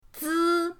zi1.mp3